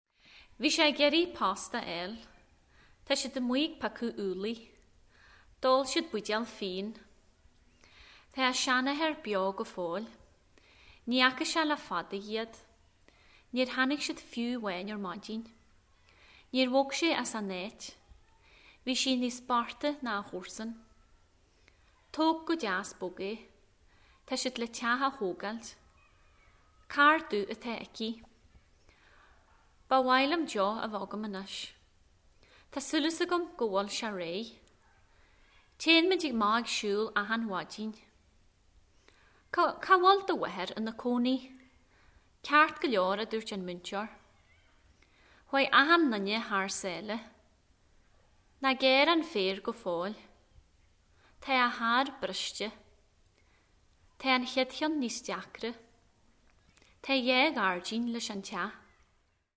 Sample sound files for Modern Irish
Each sample here is from the beginning of a recording in which the particular speaker read out a set of sentences containing tokens of the lexical sets devised for the phonology of modern Irish.
Min_an_Chladaigh_(F_30)_S.wav